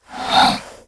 initiate_attack7.wav